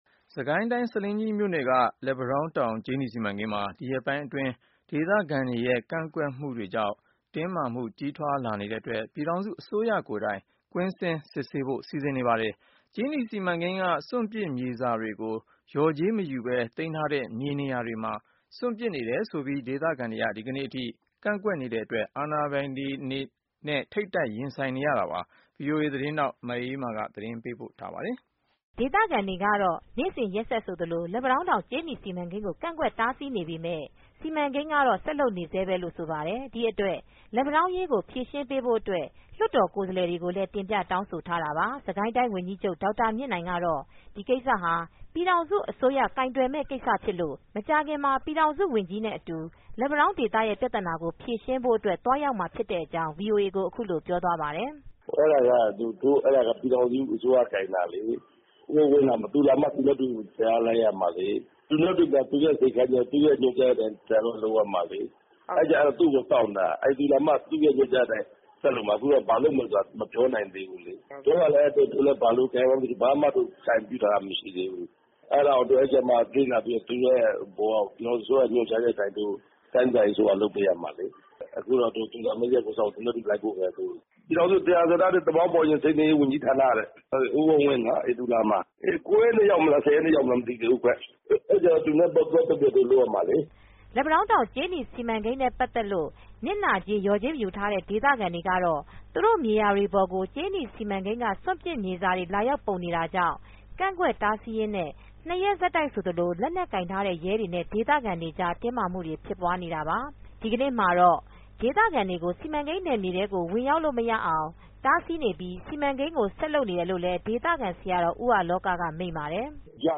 ဒေသခံတွေကတော့နေစဉ်ဆိုသလို လက်ပံတောင်းတောင်ကြေးနီစီမံကိန်းကို ကန်ကွက်တားဆီးနေပေမယ့် စီမံကိန်းကတော့ ဆက်လုပ်နေစဲပဲလို့ ဆိုပါတယ်။ ဒီအတွက် လက်ပံတောင်းအရေးကိုဖြေရှင်းပေးဖို့ အတွက် လွှတ်တော်ကိုယ်စားလှယ် တွေကိုလည်း တင်ပြတောင်းဆိုထားတာပါ။ စစ်ကိုင်းတိုင်းဝန်ကြီးချုပ်ဒေါက်တာမြင့်နိုင်ကတော့ ဒီကိစ္စဟာပြည်ထောင်စုအစိုးရကိုင်တွယ်မဲ့ကိစ္စဖြစ်လို့ မကြာခင်မှာပြည်ထောင်စု ဝန်ကြီးနဲ့ အတူ လက်ပံတောင်းဒေသပြဿနာကိုဖြှေရှင်း ဖို့ သွားရောက်မှာဖြစ်ကြောင်း VOA ကိုအခုလိုပြောသွားပါတယ်။